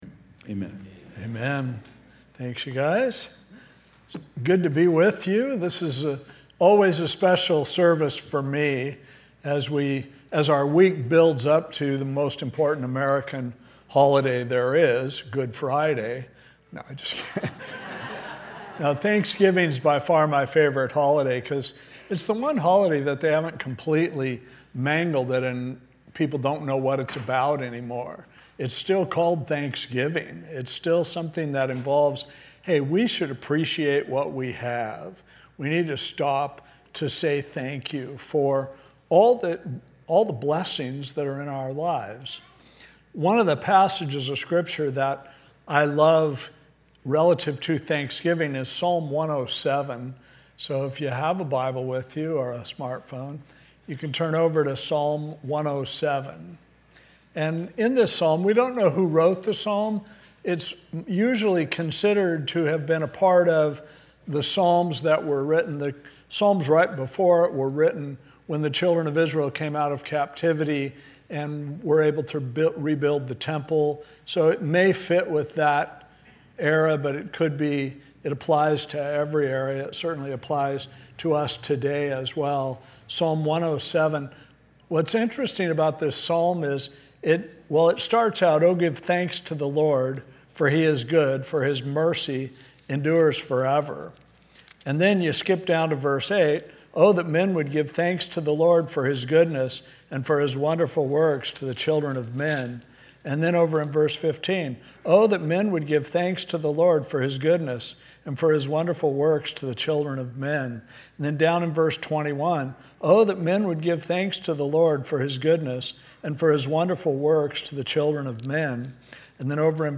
From Series: "Holiday Messages"